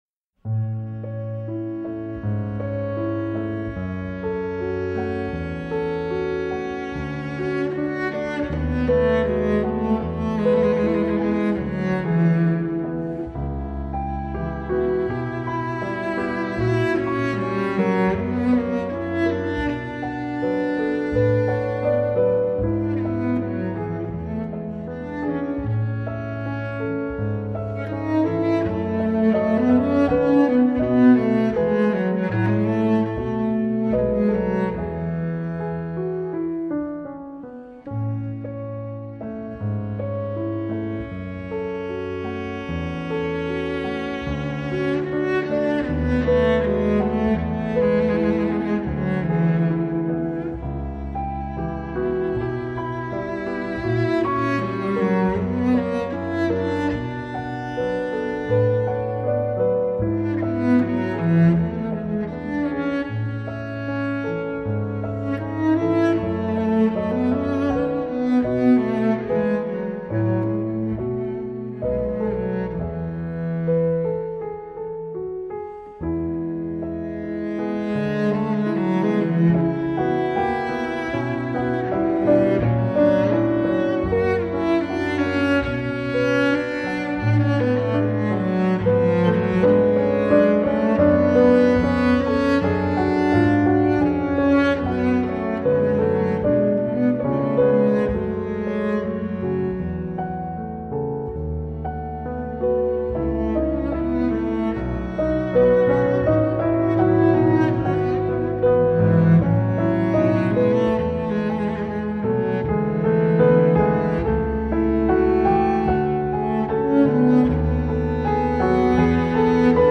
Piano and Cello